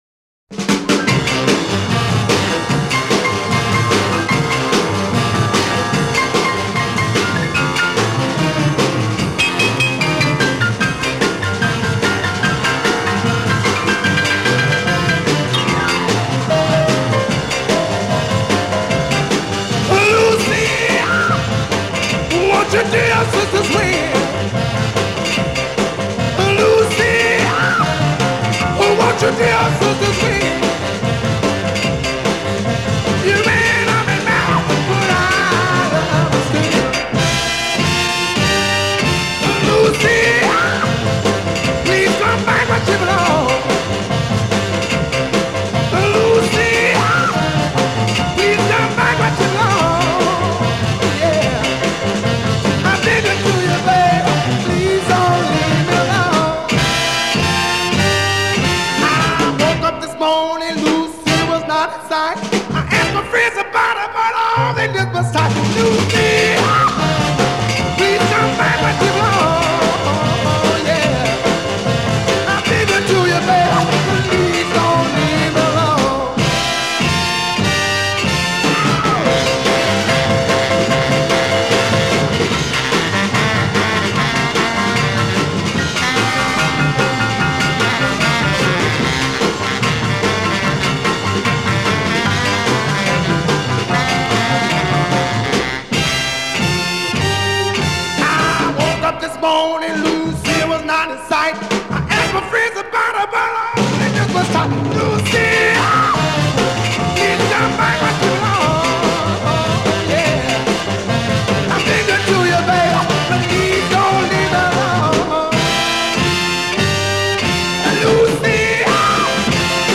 Жанр: Рок-н-ролл